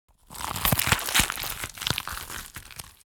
guts_and_gore_19.wav